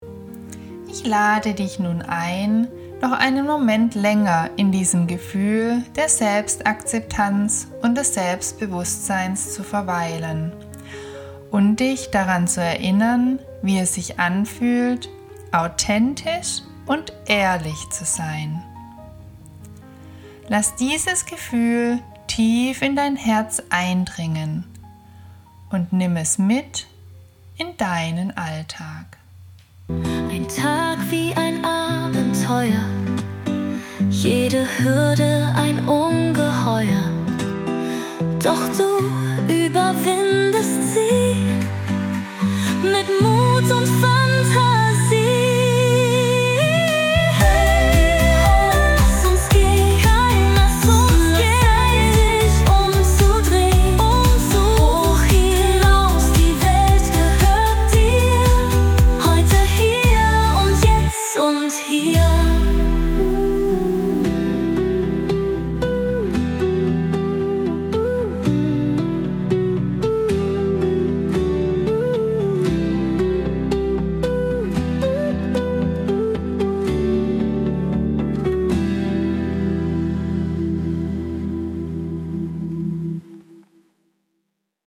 ❤ Sanfte Stimme, klare Struktur, liebevolle Begleitung
12 geführte Meditationen für innere Stärke & emotionale Balance
• Sanfte Stimme, achtsame Sprache, klare Struktur